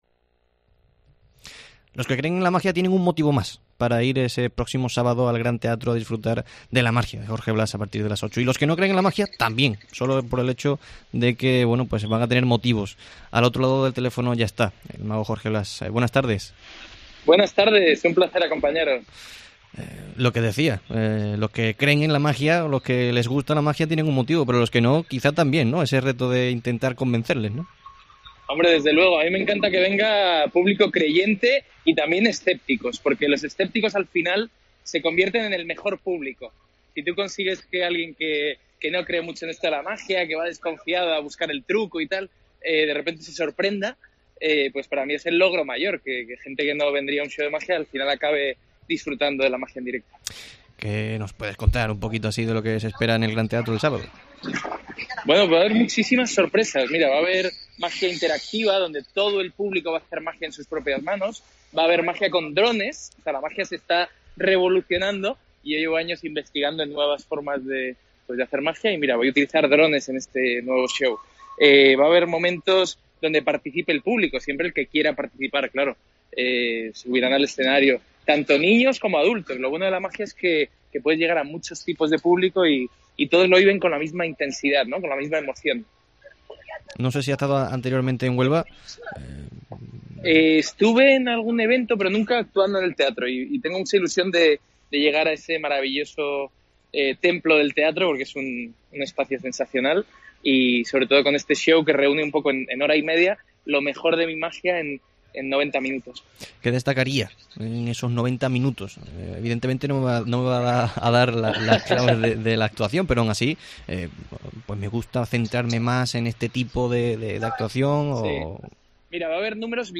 El mago Jorge Blass atiende a COPE Huelva para desgranar lo que se vivirá este próximo sábado en el Gran Teatro con su show 'Nuevas Ilusiones'